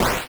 flame.wav